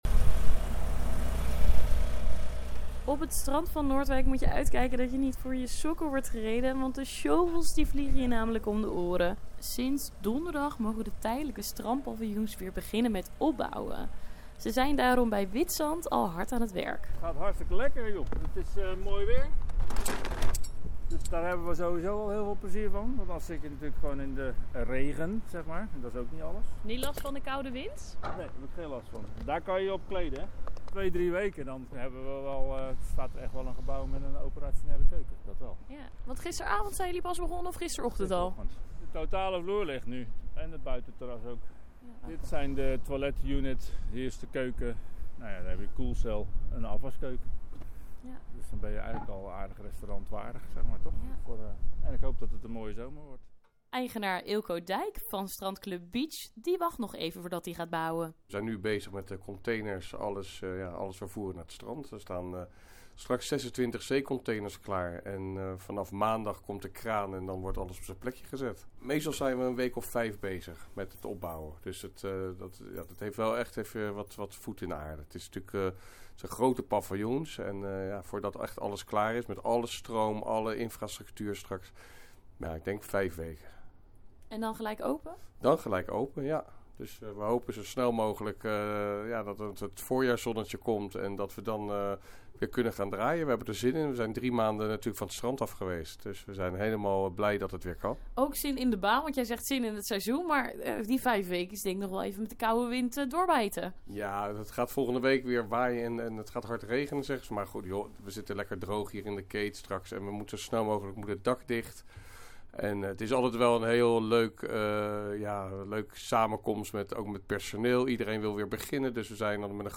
“Of het nou sneeuwt, regent of waait, we gaan gewoon door”, vertelt een van de bouwlieden die op het strand bezig is.